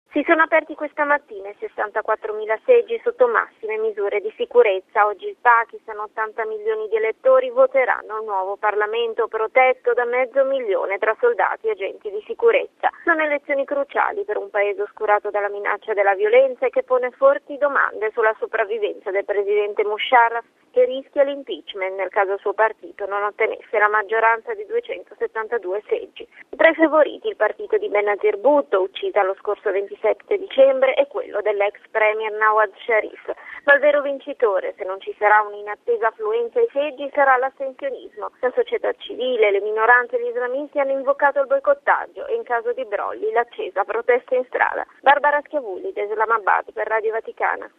Da Islamabad